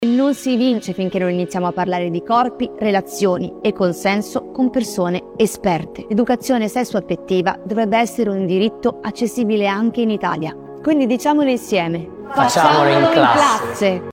Avete appena ascoltato uno spezzone del video realizzato da Save the Children insieme ad Aurora Ramazzotti. La content creator ha presentato un quiz sull’educazione sessuo-affettiva, in cui Millennials, GenZ e GenAlpha mettono alla prova la loro conoscenza sul tema.